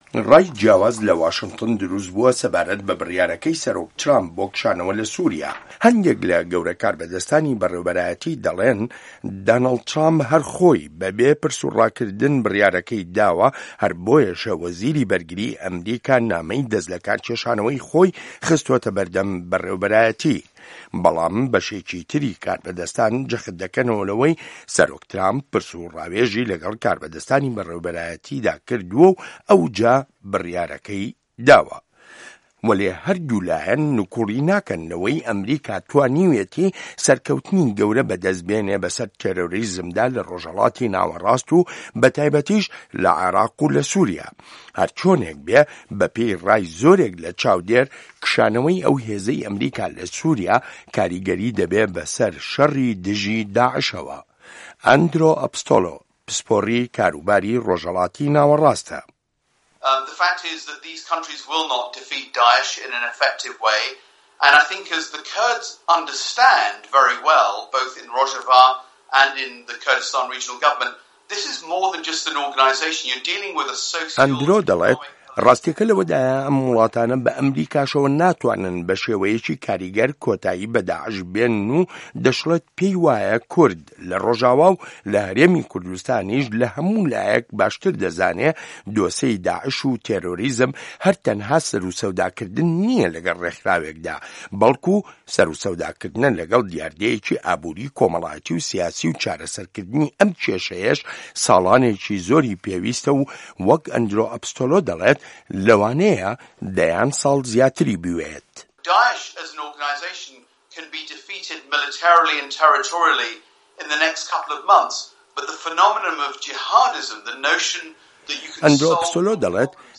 ڕاپۆرت